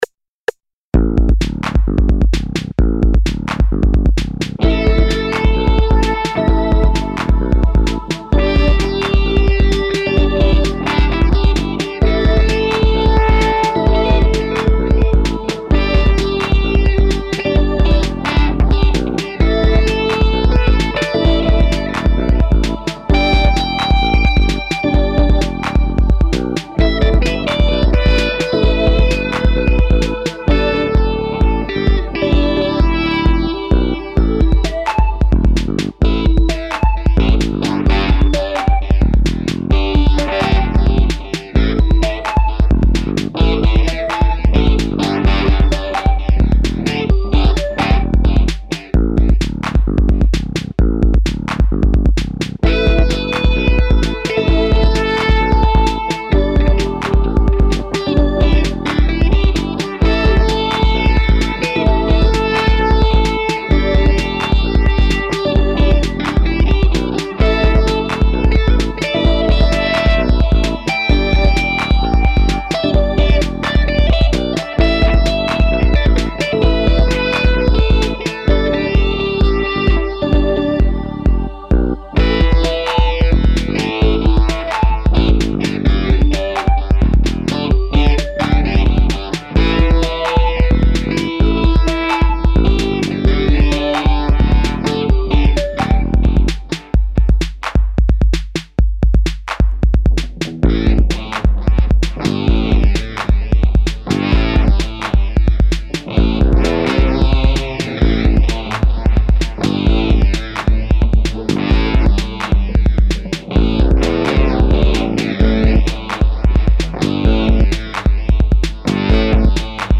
I found an old MIDI file in one of my archive folders called spacedisco.mid. I don't really remember what it was for but it just has drums, bass and a simple instrument part so I think I meant to play guitar over it. I opened it in Tracktion, and applied fake instruments to the MIDI tracks, added cheezy choir pads, and played over it (semi improvised).
I guess it's supposed to sound spacey.
Filed under: Instrumental